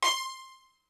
STR HIT C5.wav